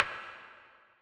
CC - Poker Perc.wav